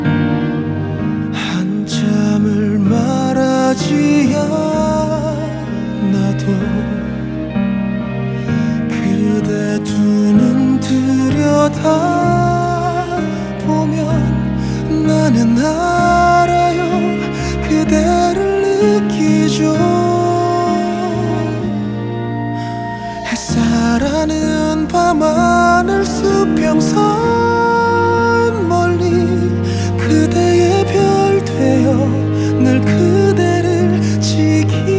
TV Soundtrack Soundtrack Pop K-Pop
Жанр: Поп музыка / Соундтрэки